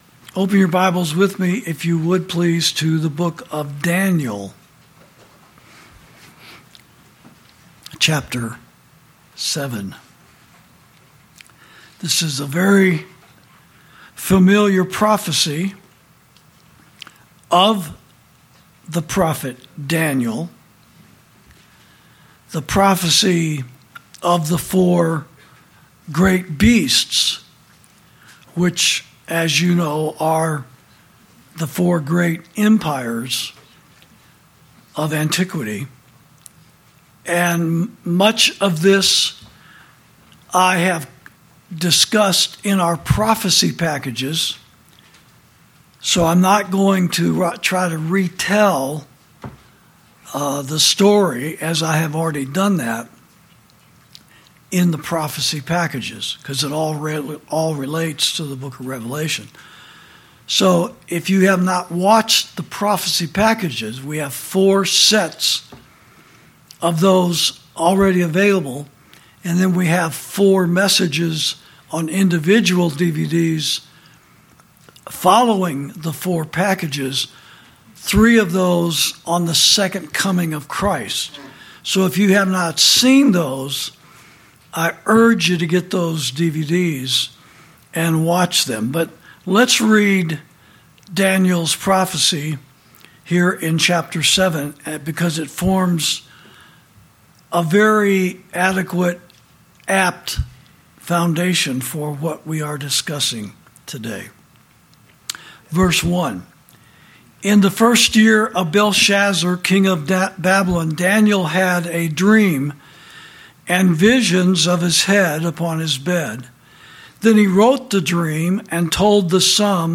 This message was preached by Pastor Chuck Baldwin on Sunday, April 12, 2026, during the service at Liberty Fellowship.